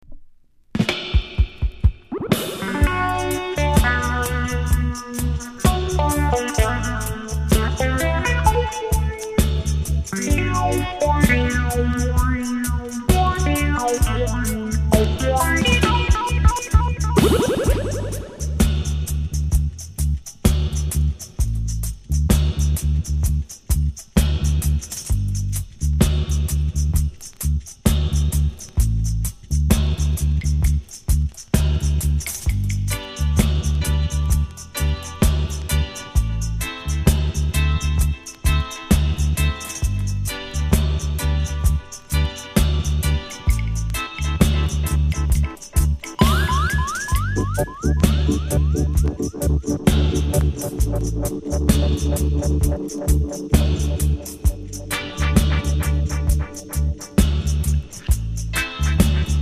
※小さなチリノイズが少しあります。
コメント NICE FEMALE LOVERS!!